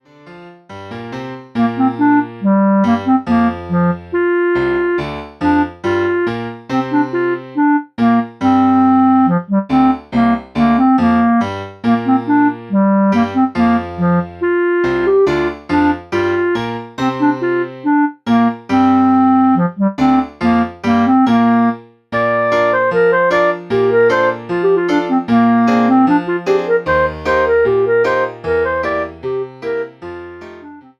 All works are written for clarinet and piano.